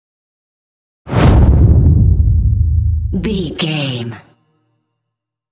Dramatic whoosh to hit deep trailer
Sound Effects
Atonal
dark
intense
tension
woosh to hit